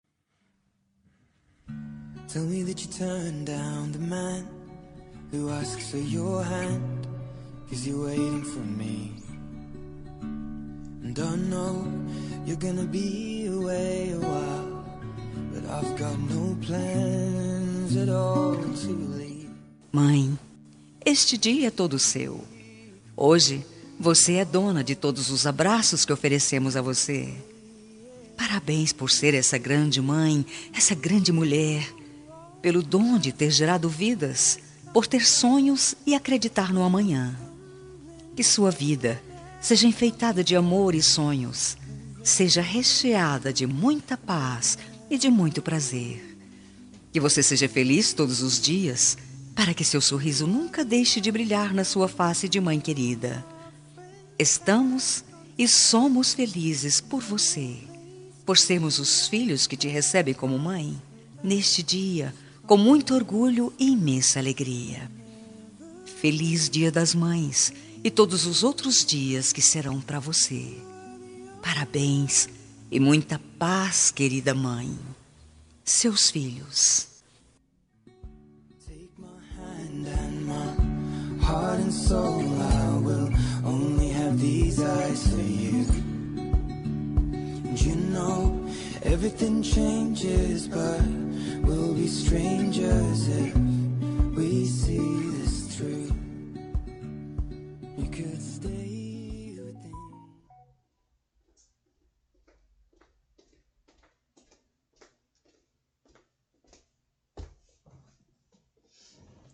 Dia das Mães – Para minha Mãe – Voz Feminina – Plural – Cód: 6510